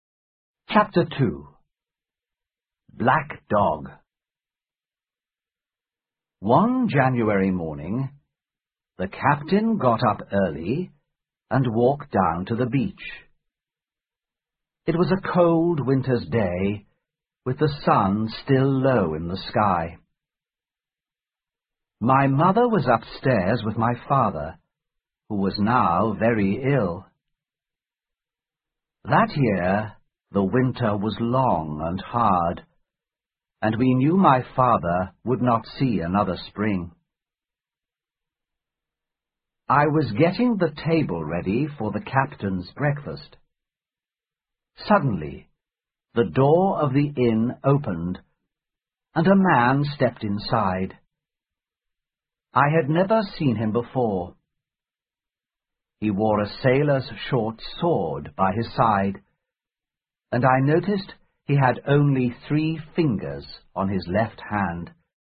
在线英语听力室《金银岛》第5期:第二章 黑狗(1)的听力文件下载,《金银岛》中英双语有声读物附MP3下载